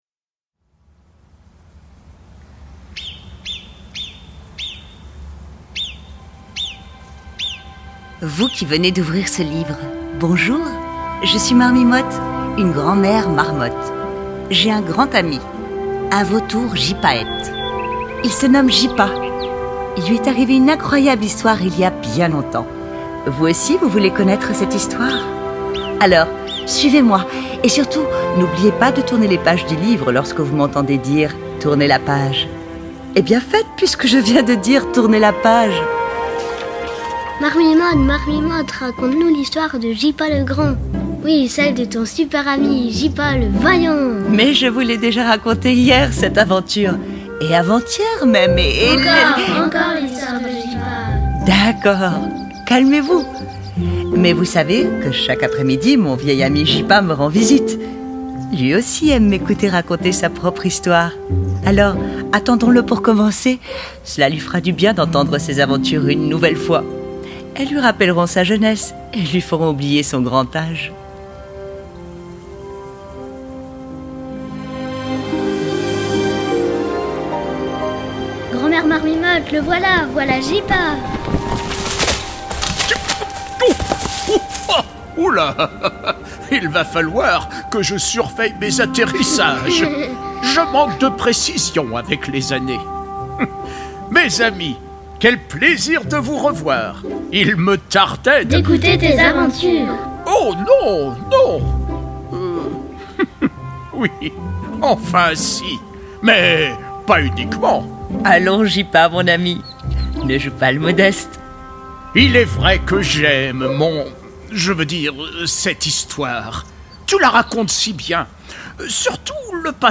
Gypa, un audio livre pour les enfants de 4 à 7 ans